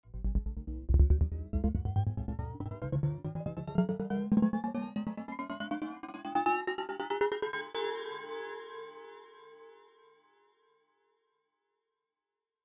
Musical Transition Sound Effect Free Download
Musical Transition